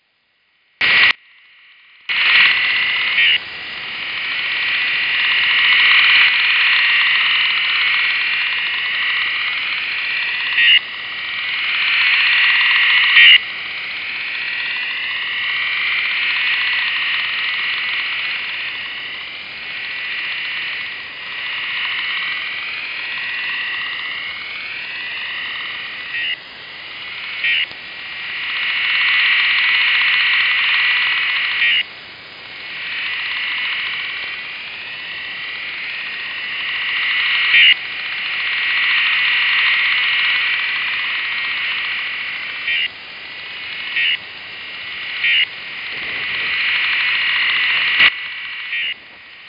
Сигнал телеуправления?
В записи характерно прослушивается своебразный "рык". Похоже на телеуправление, иногда слышен в речевых каналах КВ